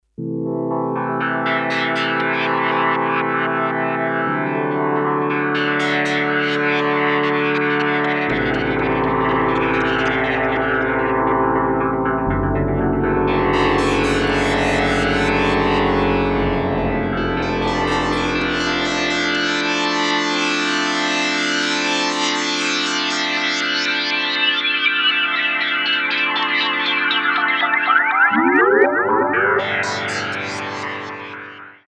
Virtual Analog Synthesizer
M-Audio Venom single patch demos